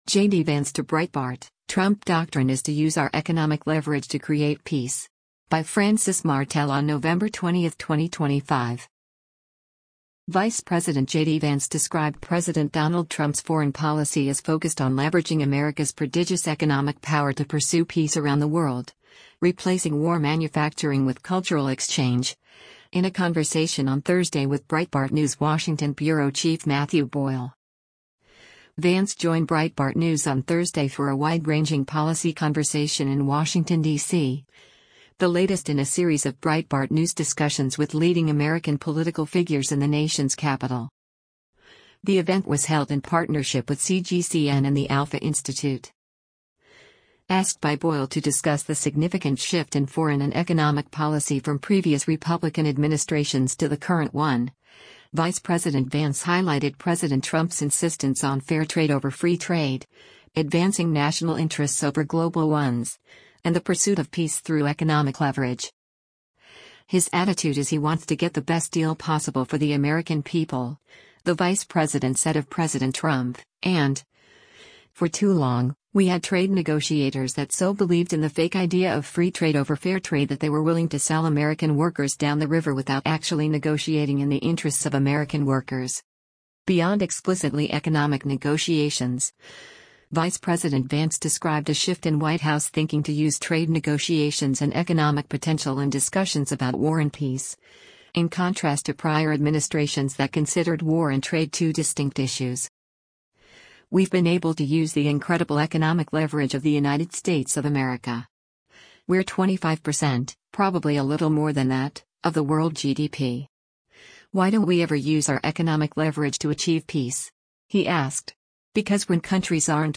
WASHINGTON, DC - NOVEMBER 20: U.S. Vice President JD Vance participates in a fireside chat
Vance joined Breitbart News on Thursday for a wide-ranging policy conversation in Washington, DC, the latest in a series of Breitbart News discussions with leading American political figures in the nation’s capital.